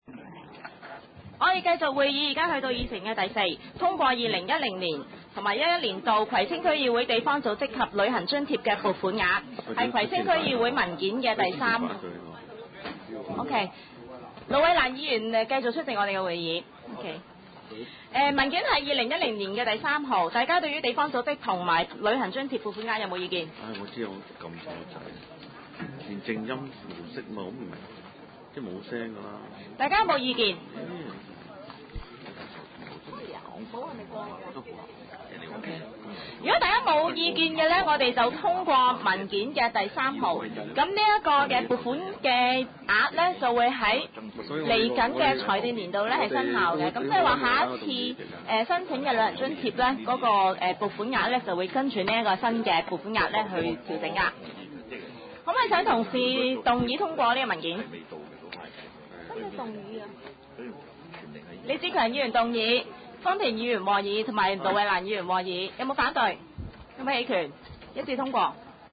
葵青區議會第六十三次會議